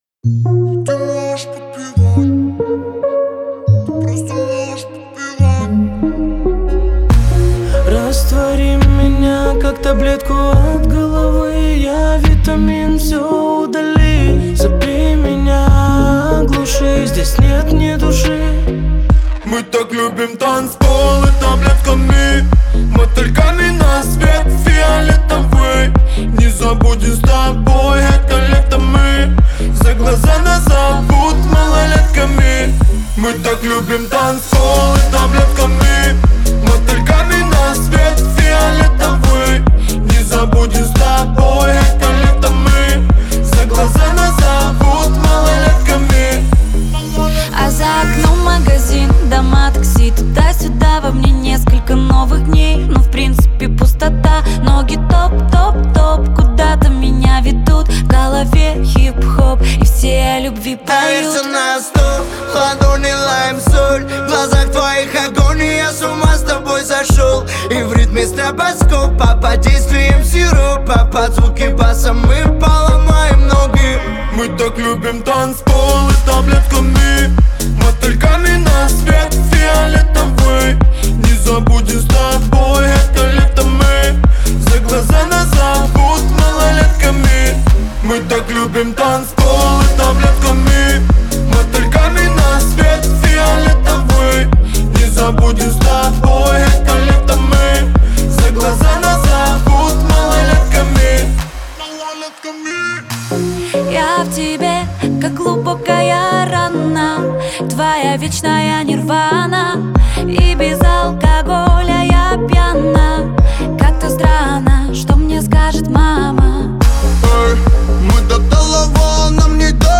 это яркий трек в жанре хип-хоп с элементами поп-музыки